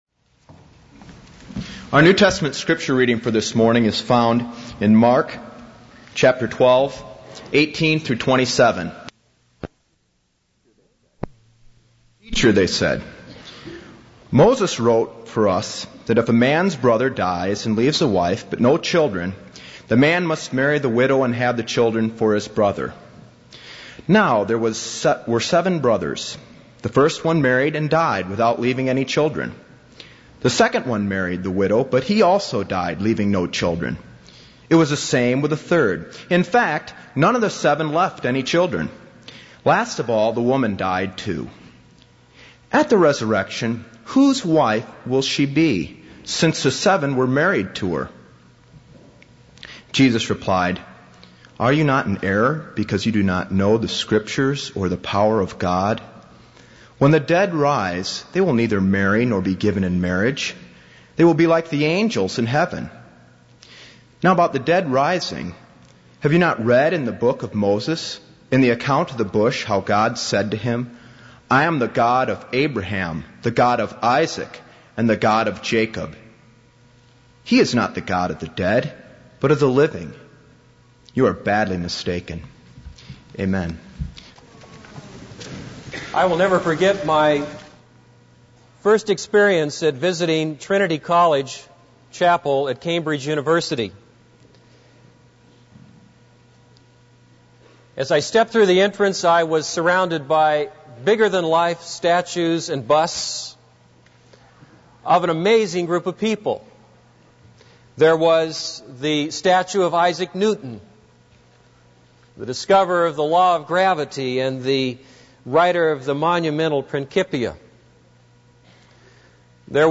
This is a sermon on Mark 12:18-27.